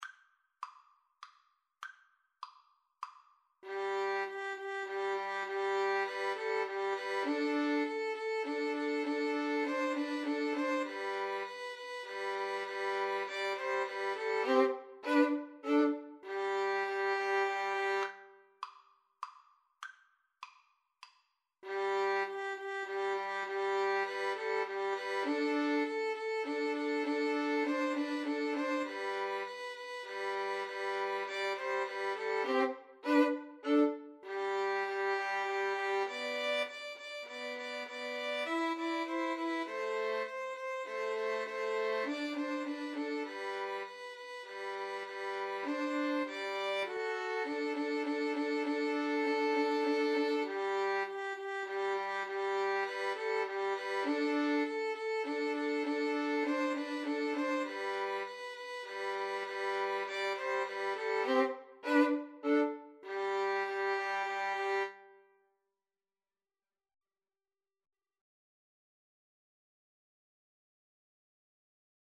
Allegro moderato (View more music marked Allegro)
G major (Sounding Pitch) (View more G major Music for Violin Trio )
3/4 (View more 3/4 Music)